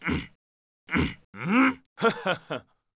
fishing_catch_v.wav